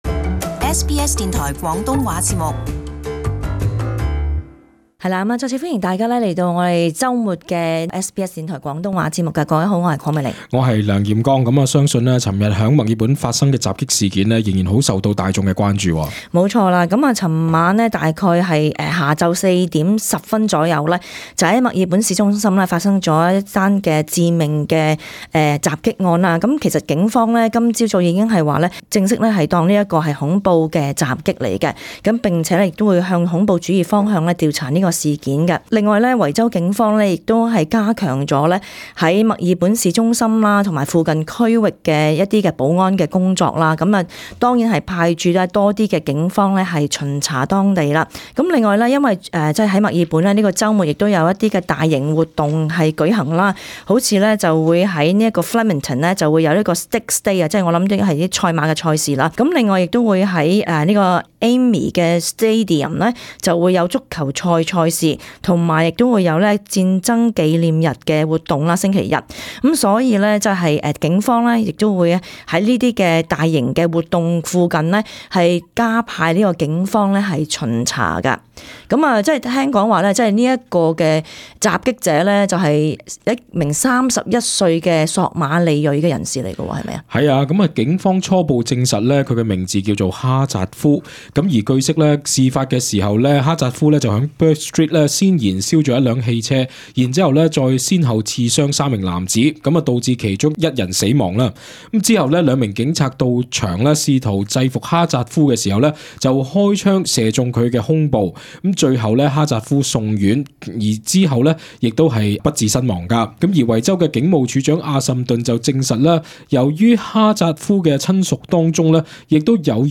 【時事報導】維州警方公布墨爾本襲擊案行兇者身份